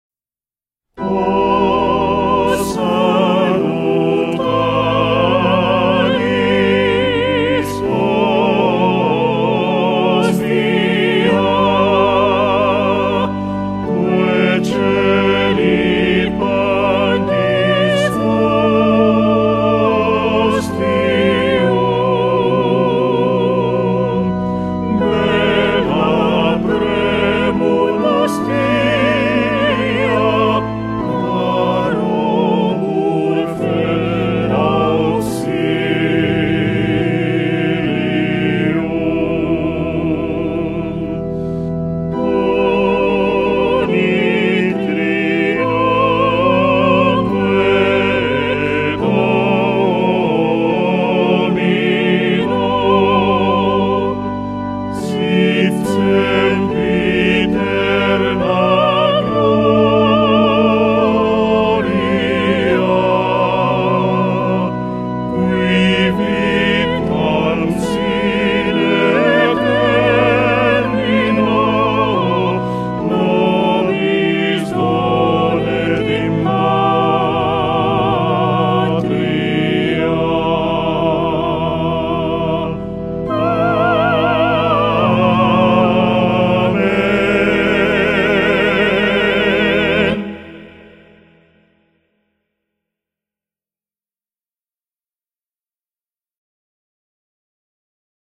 O salutaris Hostia, Canto de Alabanza